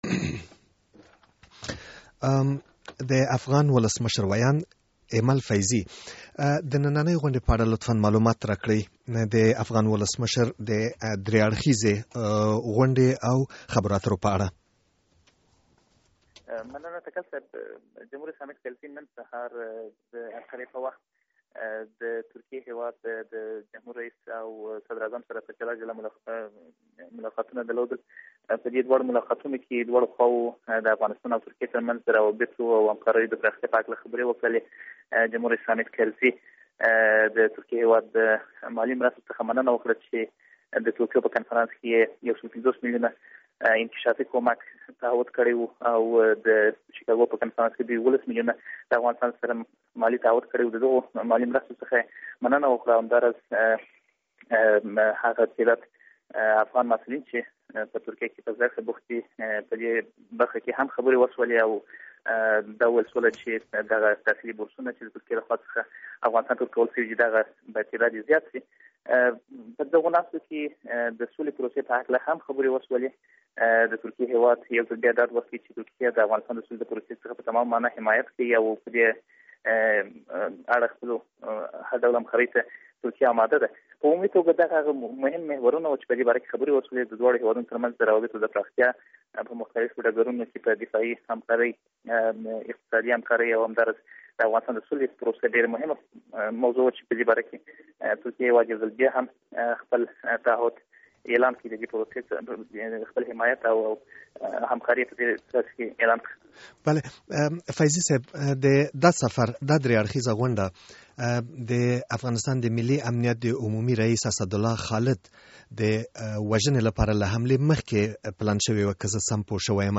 له ایمل فیضي سره مرکه